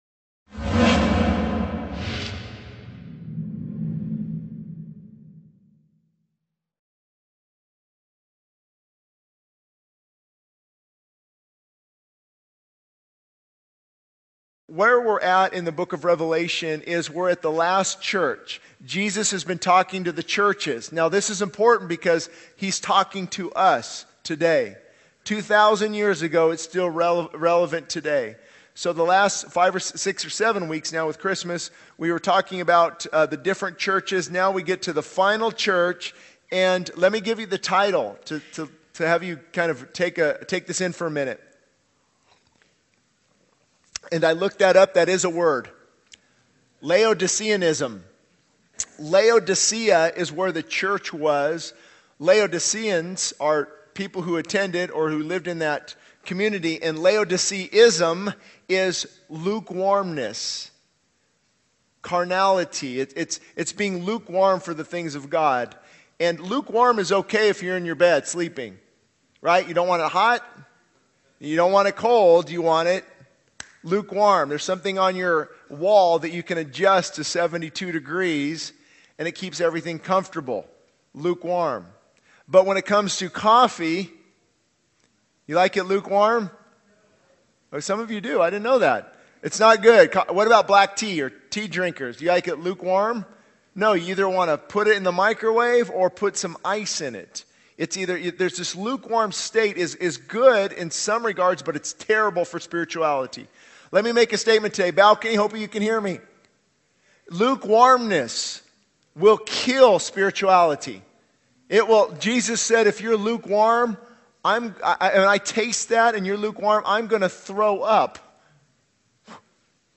This sermon emphasizes the dangers of lukewarmness in one's faith, using the example of the church of Laodicea in the book of Revelation. The speaker highlights the need for repentance, zeal, and spiritual growth through denying self and engaging in prayer and fasting. The importance of seeking God with all one's heart and the power of a life saturated in prayer are emphasized as key elements to overcome lukewarmness and grow spiritually.